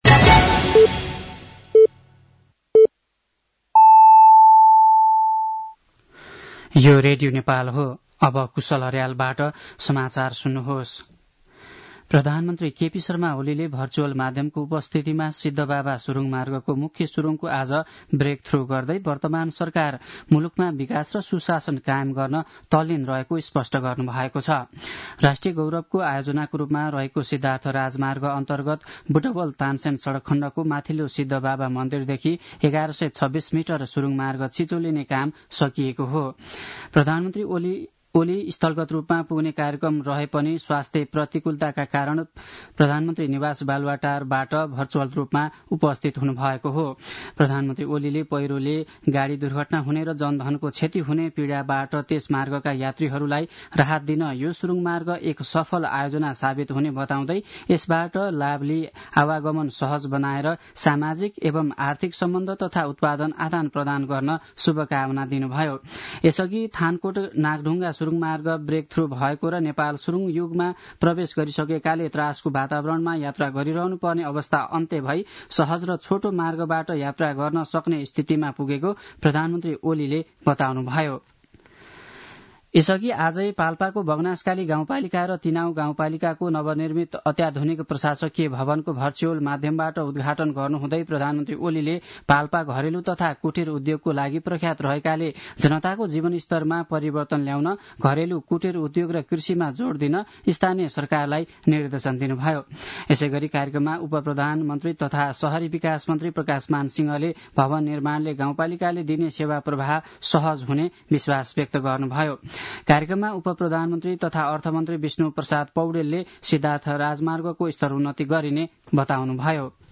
साँझ ५ बजेको नेपाली समाचार : १२ माघ , २०८१
5-pm-nepali-news-10-11.mp3